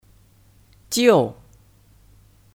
就 (Jiù 就)